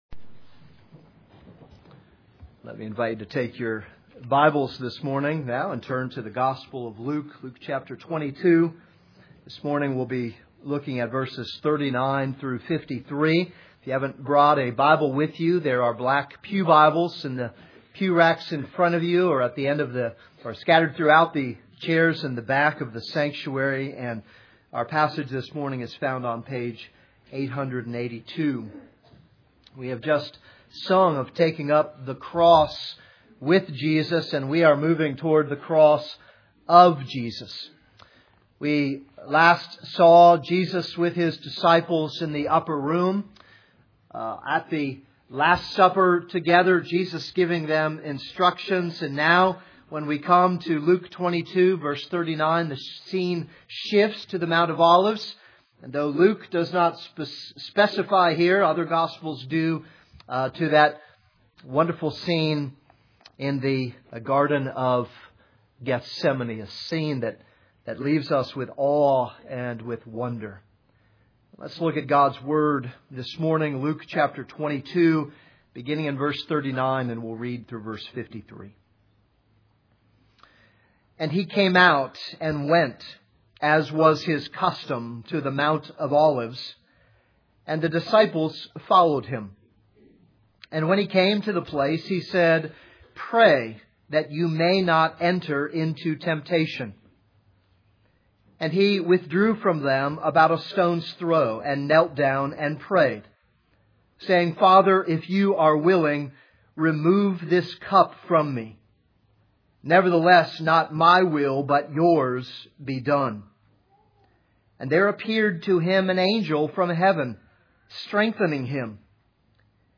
This is a sermon on Luke 22:39-53.